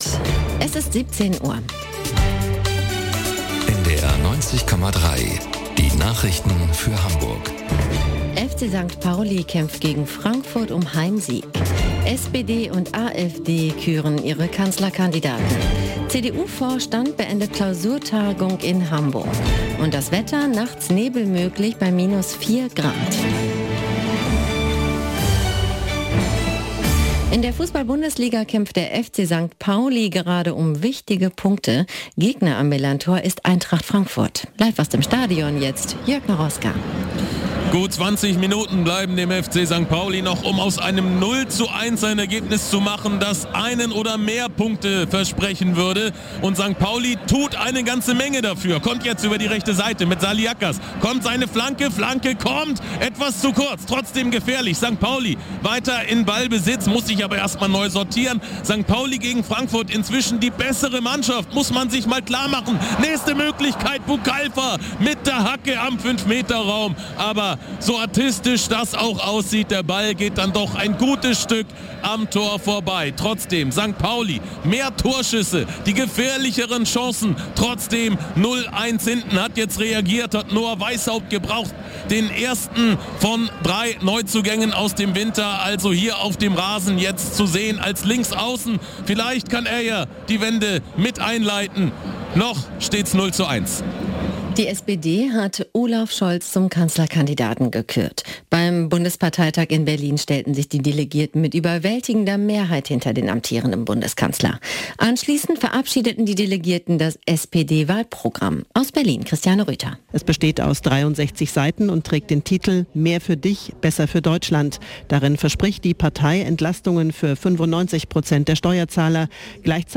1 Nachrichten 4:43